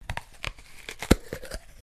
Depth Charge Shorter
Large depth charge short. WWII explosions.